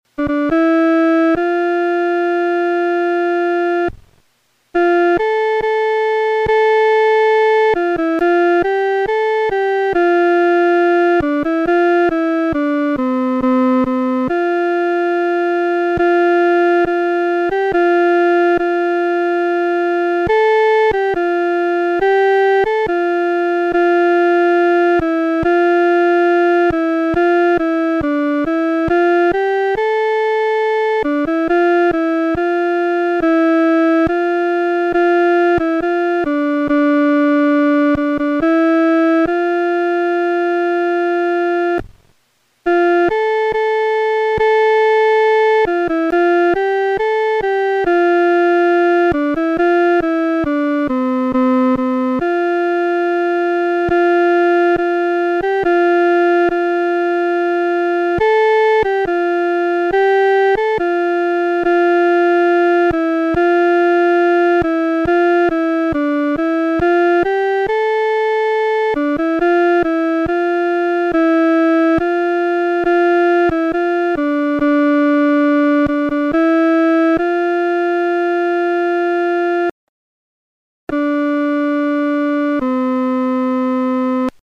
伴奏
女低
本首圣诗由网上圣诗班 （南京）录制
此曲主要刻划梅花洁白、清新的形象，曲调活泼，节奏明快，故改编者选择用它为《诗篇》第100篇所用。